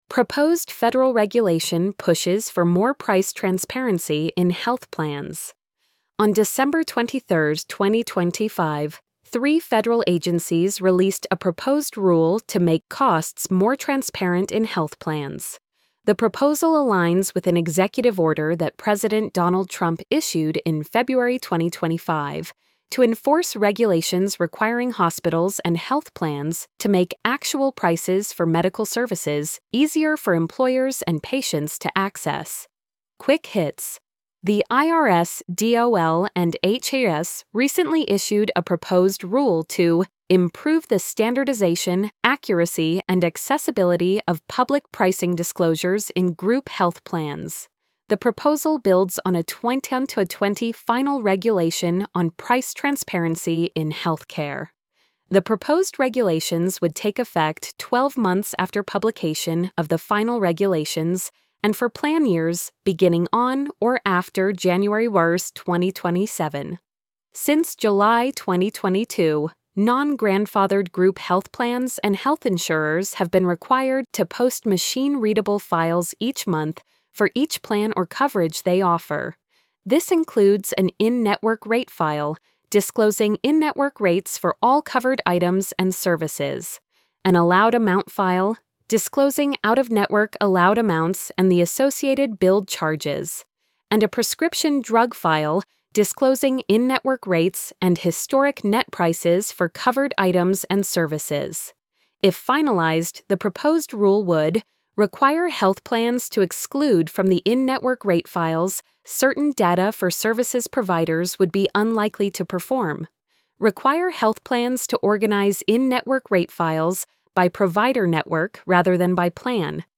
proposed-federal-regulation-pushes-for-more-price-transparency-in-health-plans-tts.mp3